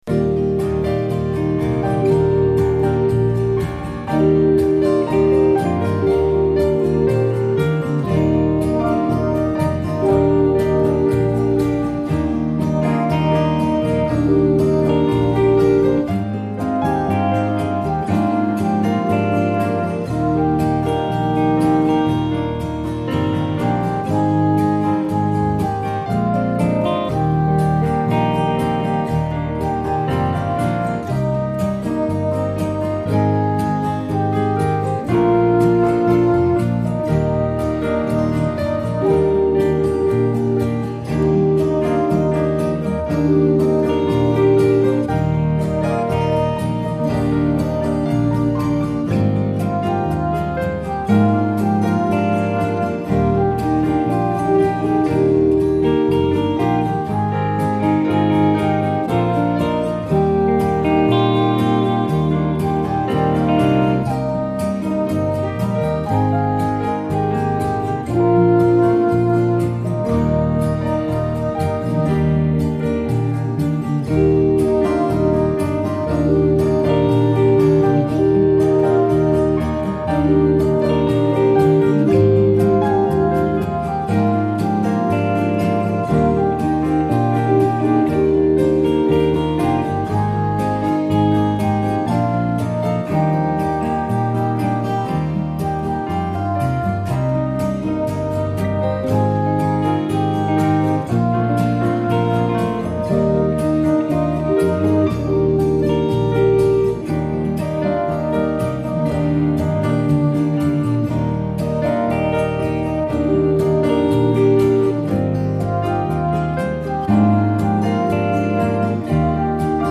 It is more subtle and gentle than most.